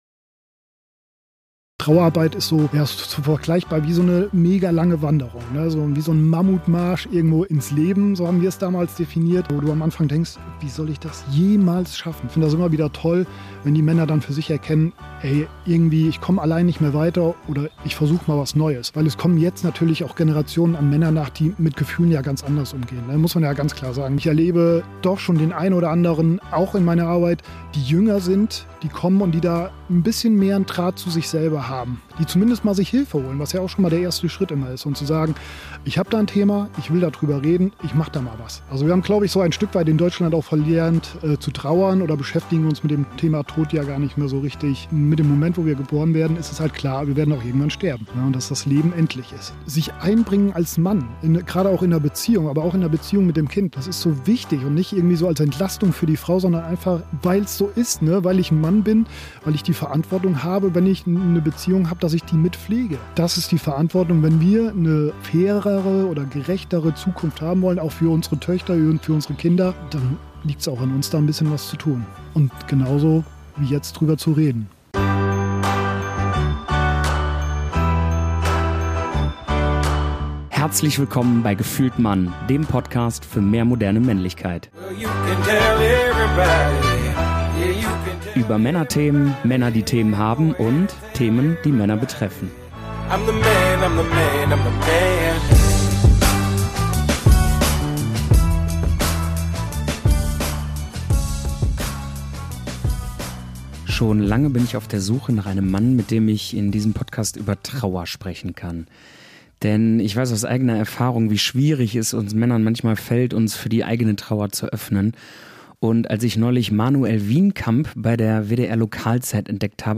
Ich finde, es ist ein lebendiges Gespräch.
Übrigens: Wir haben uns an einem ganz besonderen Ort getroffen, weshalb es hin und wieder mal ein paar Nebengeräusche gibt, die aber nicht weiter stören sollten.